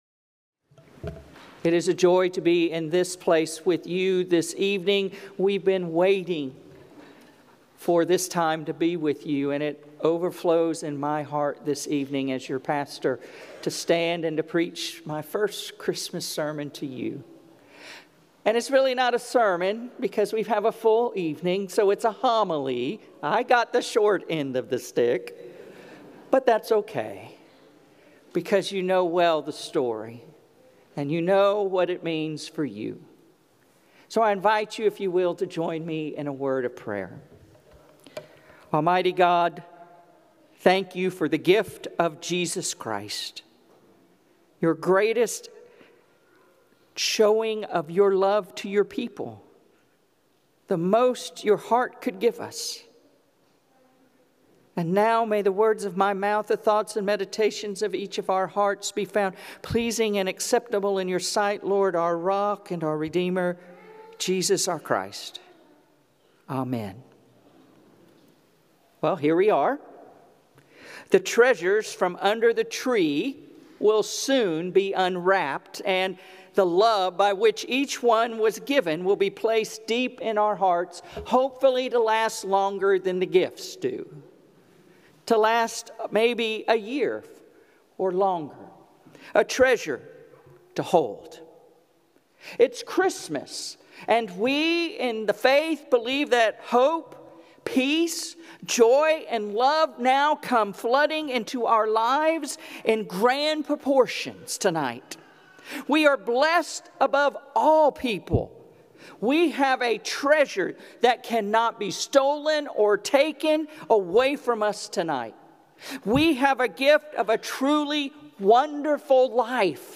Sermons | Kingswood Church in Dunwoody, Georgia